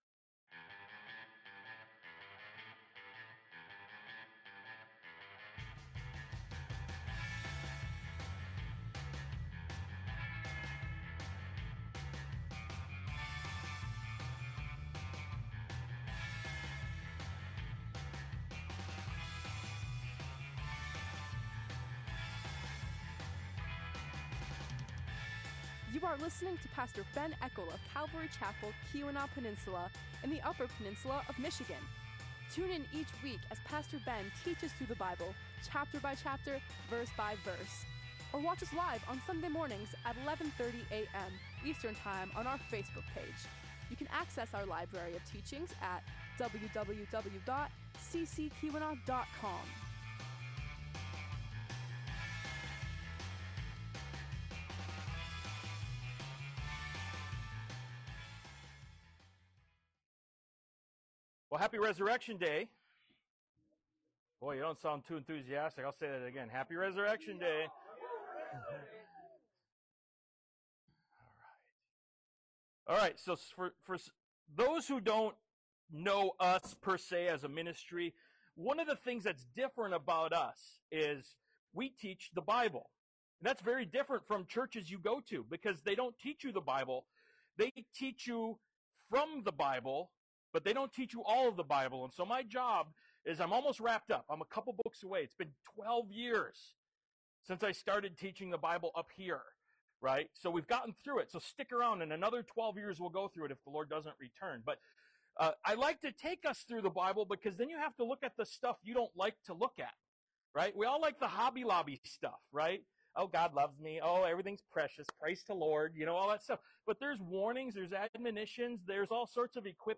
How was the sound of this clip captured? Resurrection-Sunday-2023.mp3